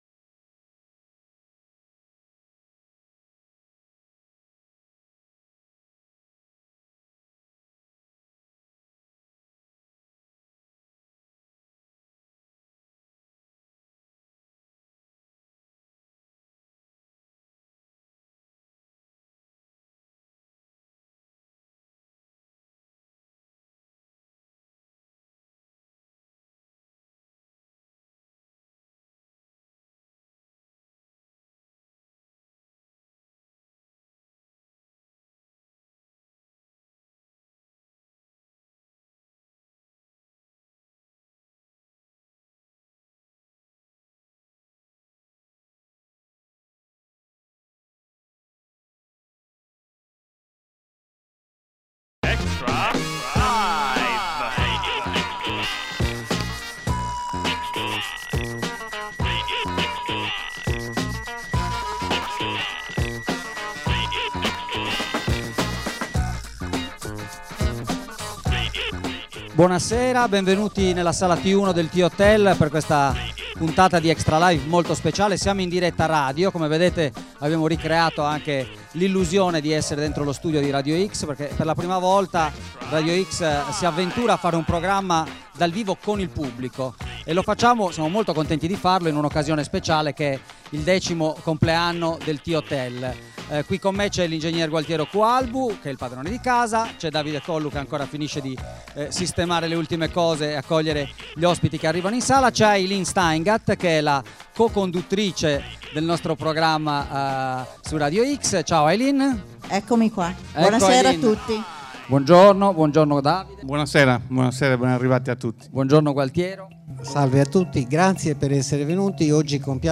Per fare impresa serve visione e voglia di innovare: puntata speciale di Extralive! in diretta dal THOTEL per festeggiare i 10 anni della struttura alberghiera realizzata sullo scheletro di un vecchio progetto della SIP. Protagonisti, amici, ospiti: un tuffo nel passato per raccontare come è cambiata Cagliari in questi 10 anni e provare a guardare oltre, verso il prossimo futuro.